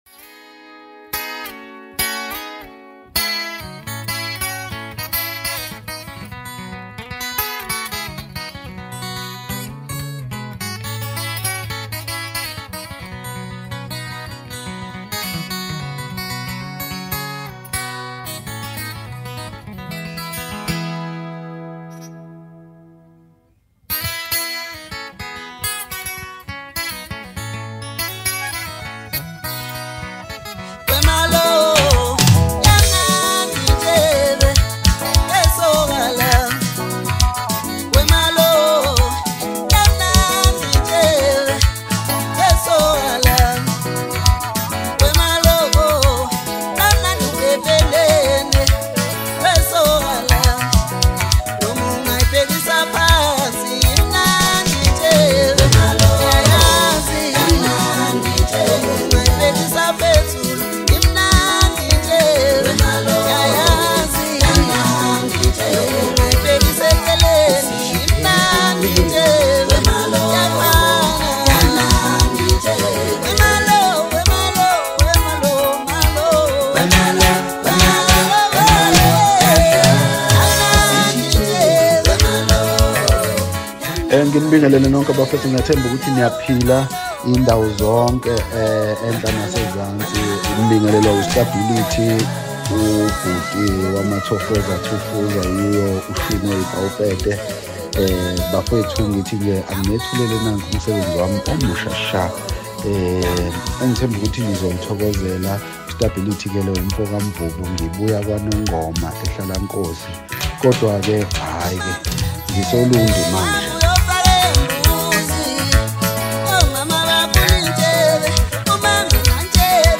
Home » Maskandi » Gqom